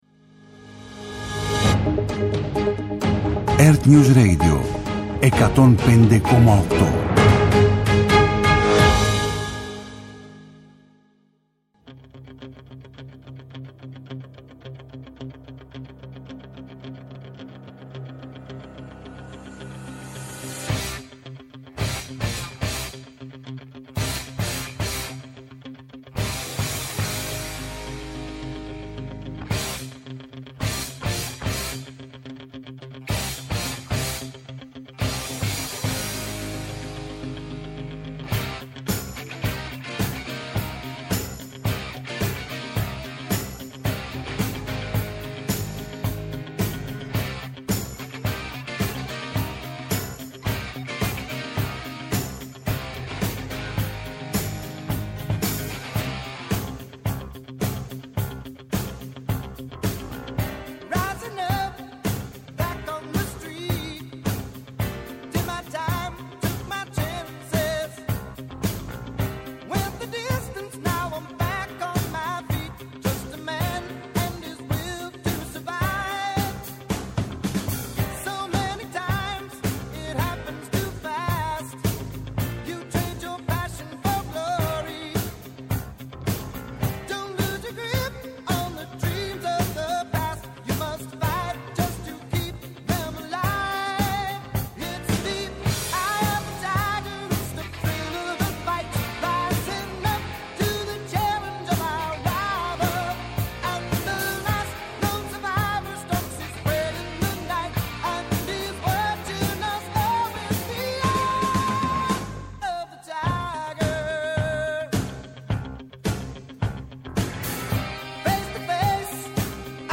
Όλη η επικαιρότητα, με αναλύσεις, ρεπορτάζ, αποκλειστικές συνεντεύξεις και μοναδικά μουσικά αφιερώματα.